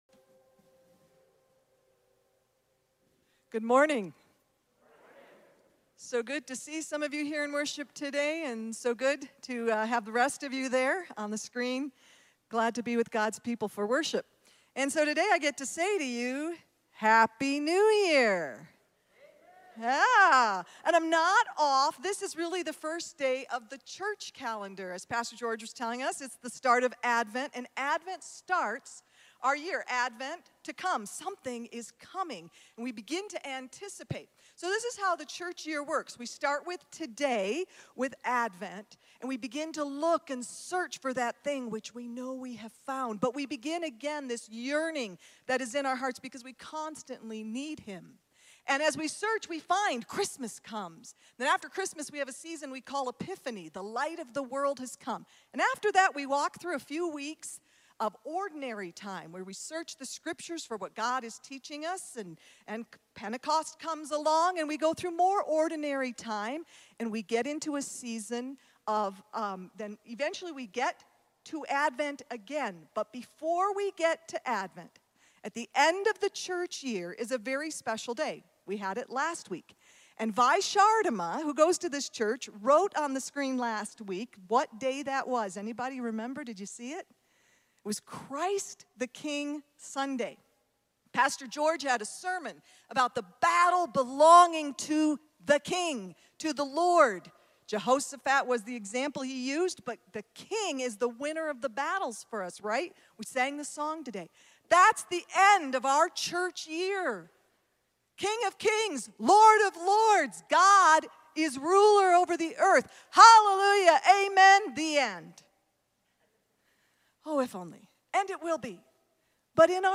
A sermon from the series "Christmas In Focus."